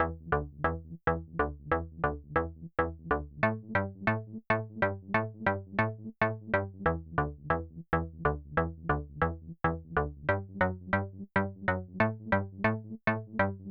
VTS1 Selection Kit Bassline